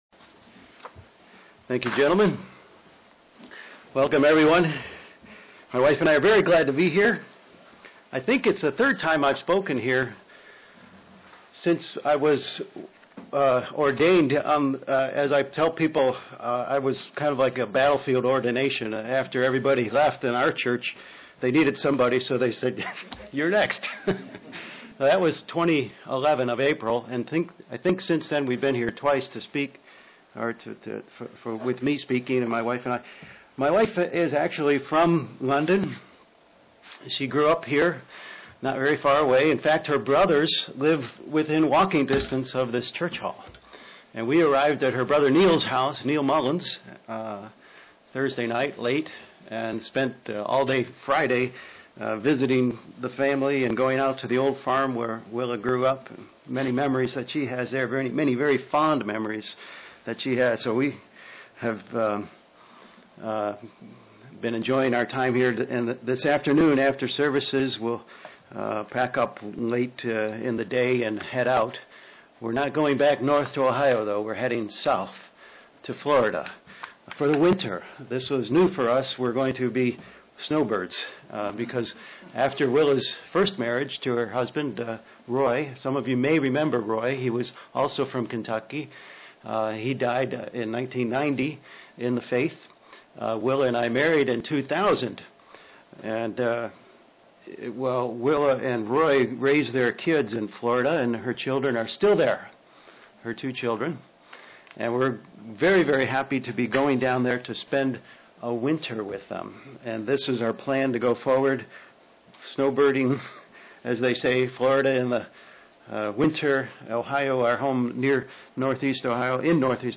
Print Peace will come to this earth when the Kingdom of God comes UCG Sermon Studying the bible?